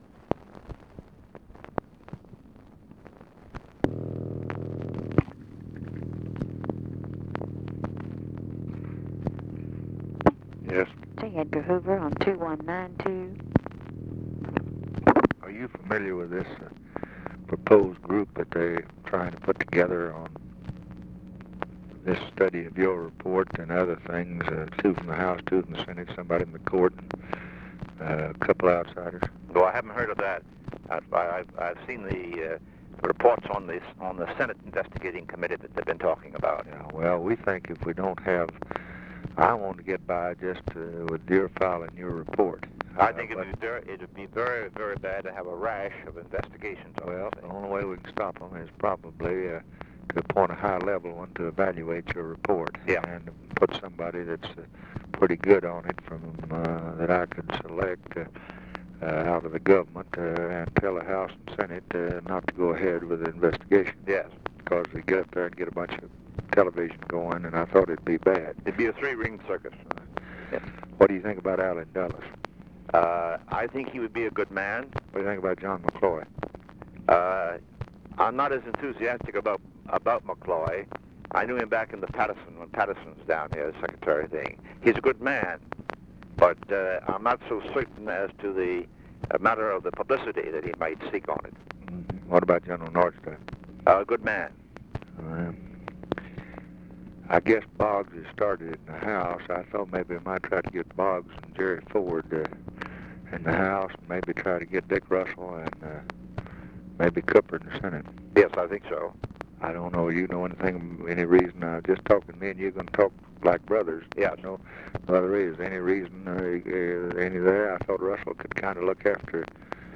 Conversation with J. EDGAR HOOVER, November 29, 1963
Secret White House Tapes